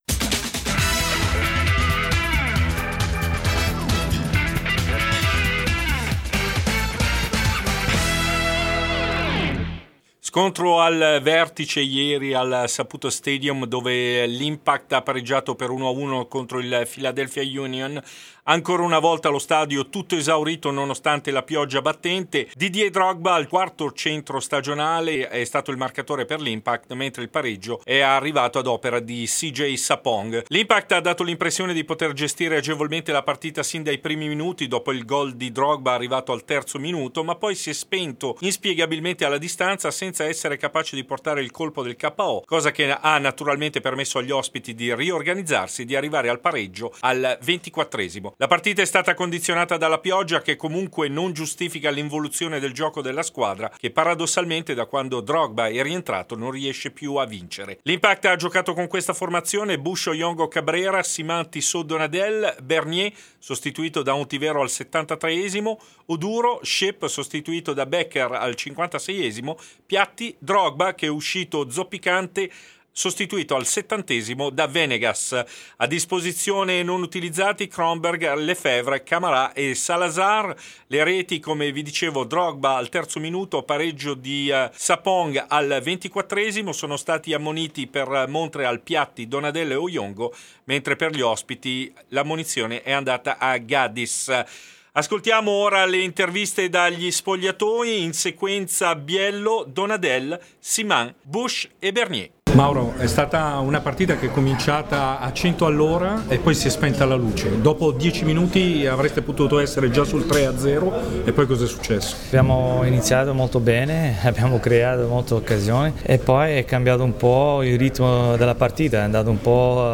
Servizio completo ed interviste del post-partita